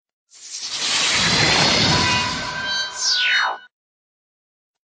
Tono Message alert